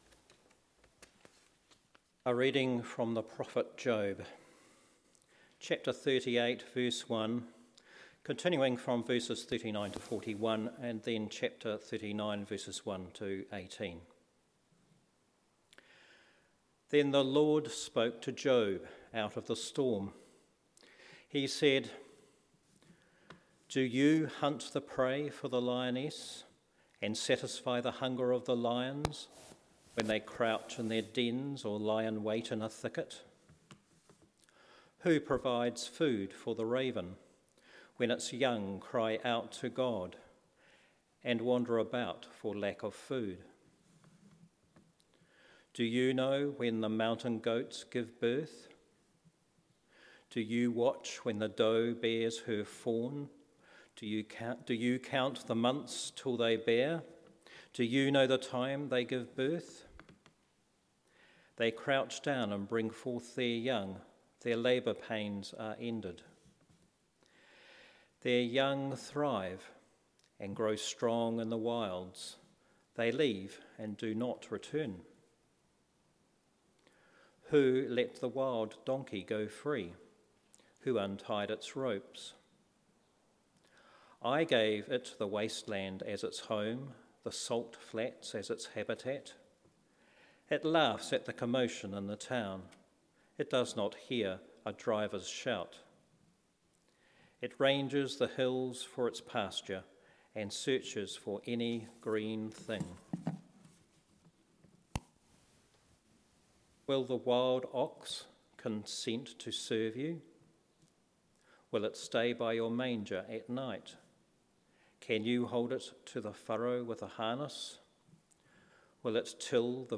on the Feast of Christ in All Creation.